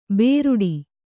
♪ bēruḍi